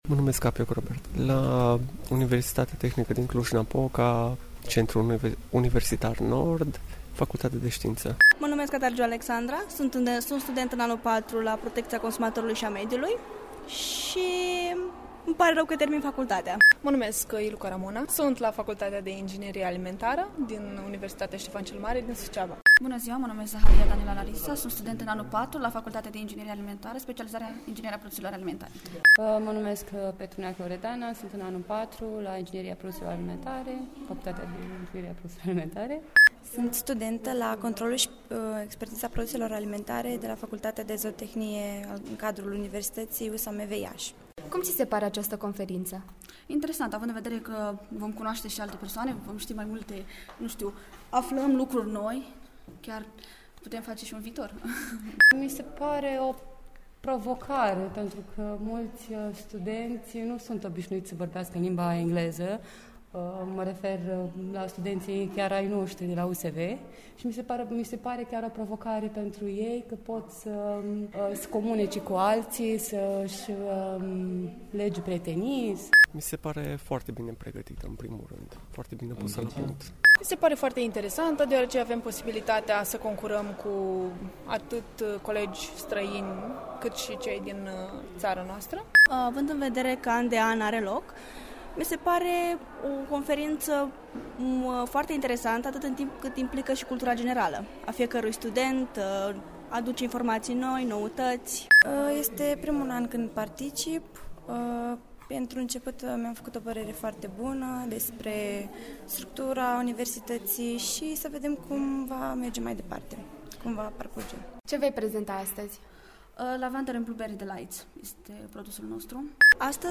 Mai jos, puteţi asculta şi păreri ale studenţilor despre competiţie: